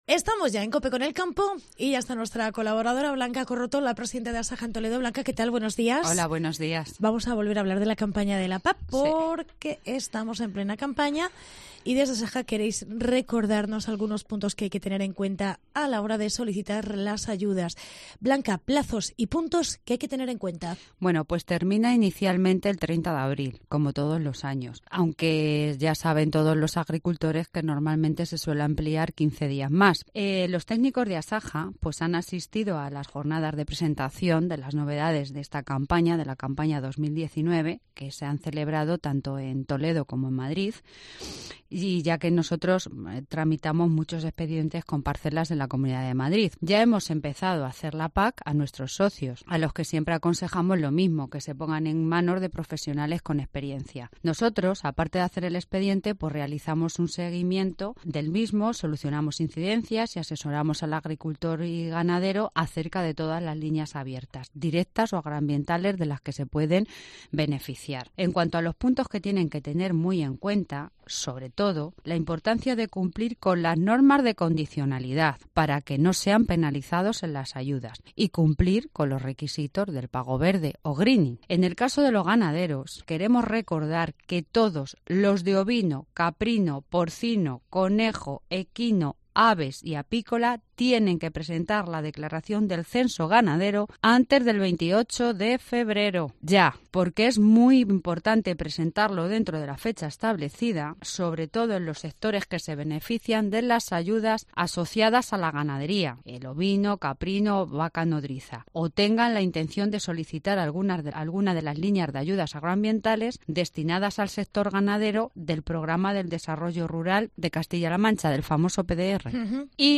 Lo que deberías saber si vas a solicitar las ayudas de la PAC. Entrevista